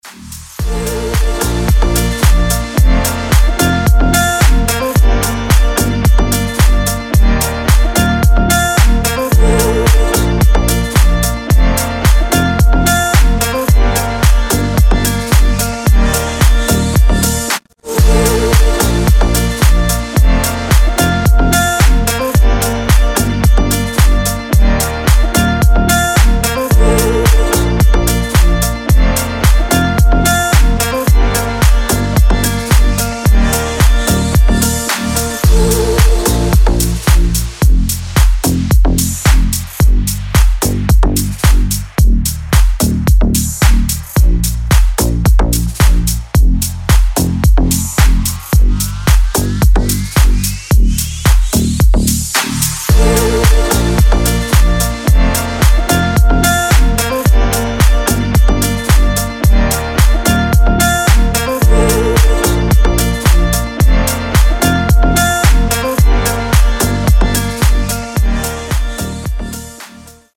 • Качество: 320, Stereo
remix
deep house
женский голос
спокойные
красивая мелодия
Красивый ремикс песни